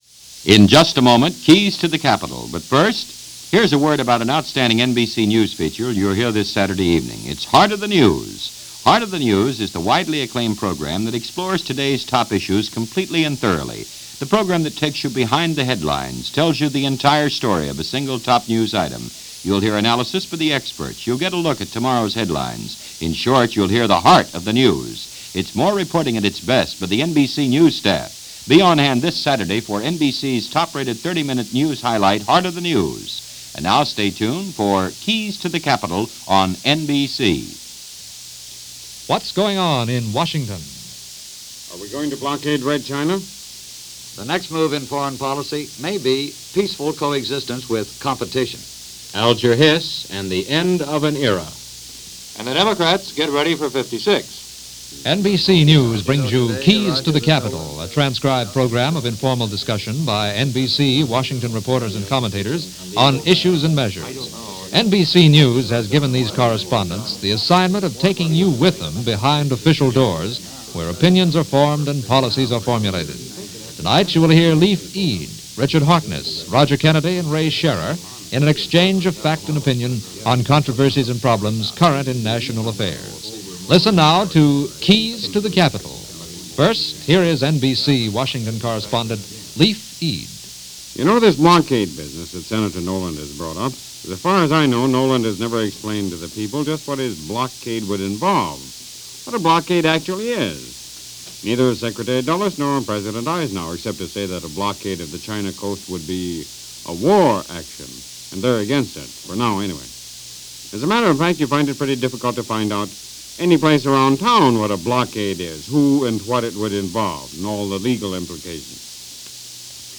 NBC-Radio-Keys-To-The-Capitol-December-1-1954.mp3